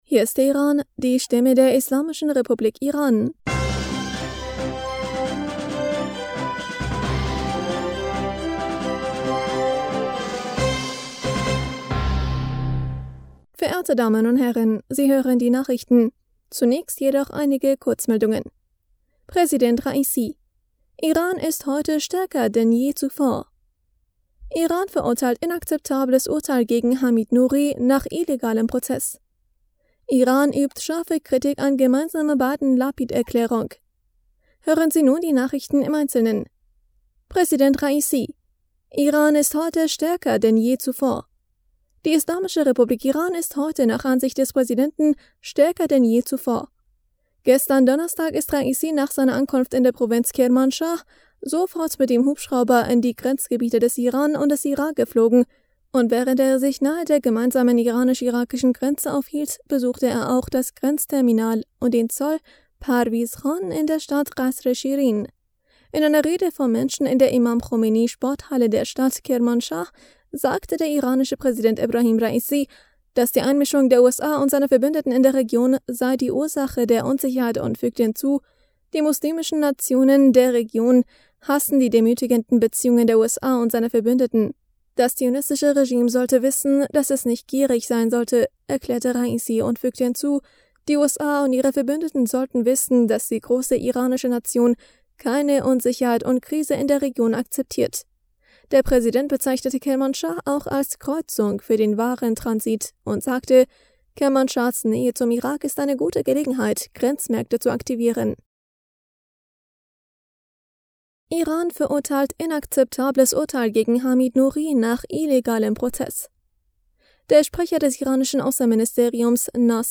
Nachrichten vom 15. Juli 2022
Die Nachrichten von Freitag, dem 15. Juli 2022